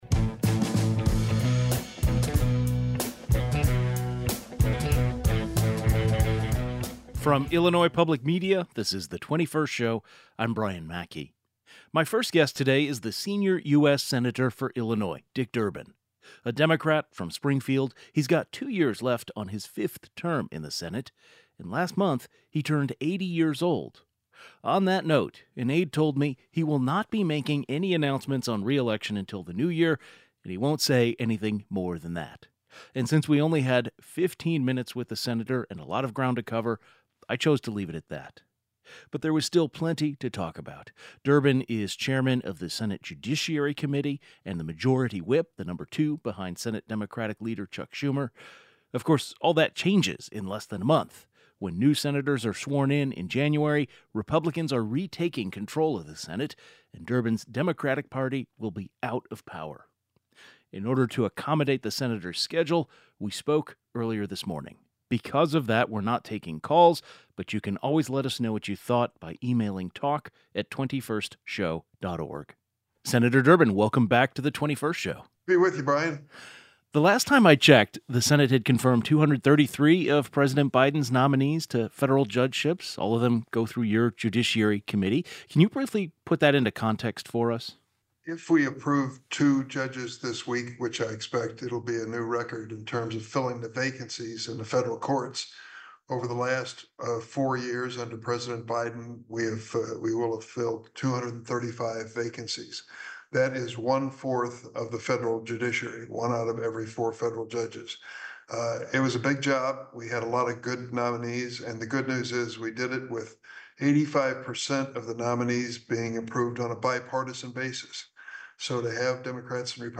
He joins the program to give his thoughts on the Democrats’ return to the minority in the Senate, President-elect Donald Trump's cabinet picks, and current President Joe Biden's judicial nominees. GUEST Senator Dick Durbin D-Illinois, Chairman of the Senate Judiciary Committee Tags